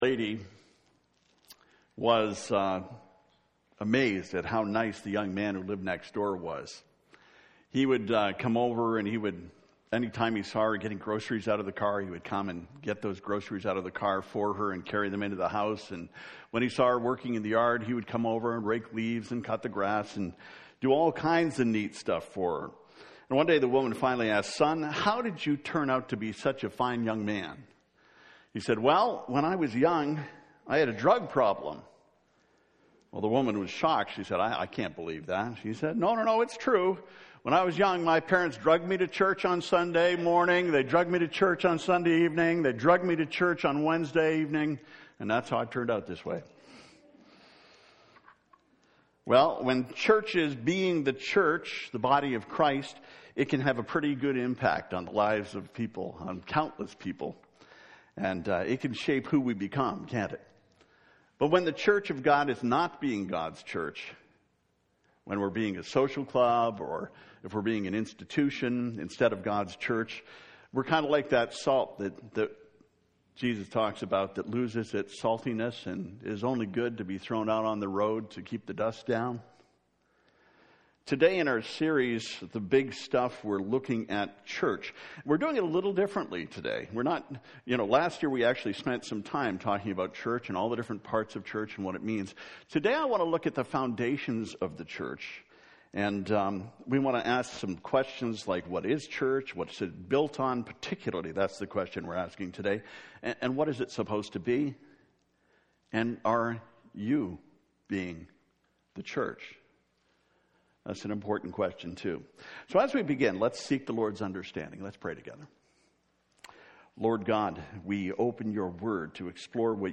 03-Sermon-Church-1.mp3